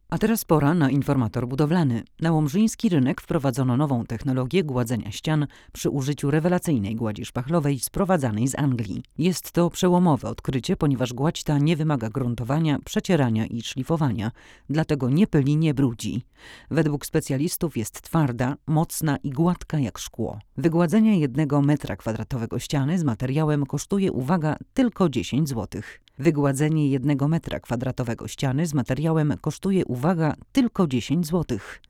Nagranie wokal żeński
Nowa 67ka praktycznie nie szumi.